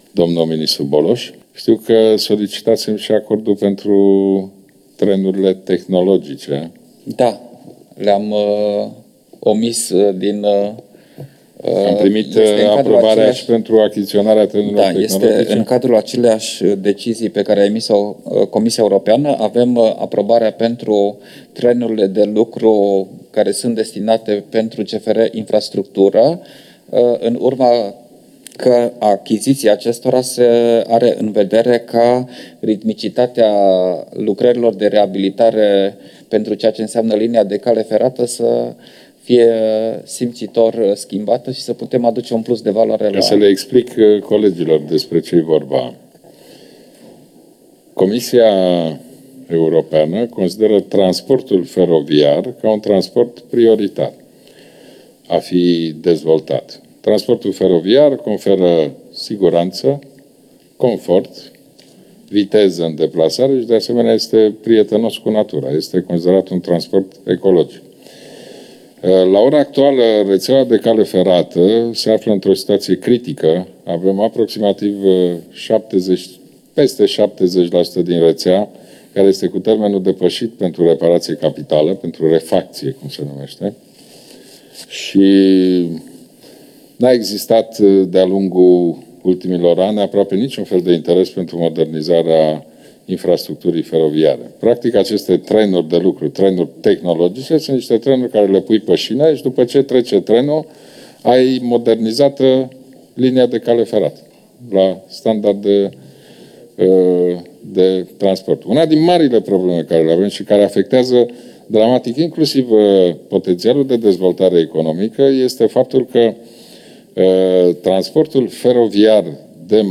Ludovic Orban a declarat, înaintea ședinței de Guvern, că nu a existat de-a lungul ultimilor ani aproape niciun fel de interes pentru modernizarea infrastructurii feroviare.
La rândul său, ministrul Fondurilor Europene, Marcel Boloș, a declarat că există aprobarea CE pentru achiziția de trenuri de lucru pentru CFR Infrastructură, astfel încât ritmicitatea pentru lucrările de reabilitare a căii ferate va fi simțitor schimbată.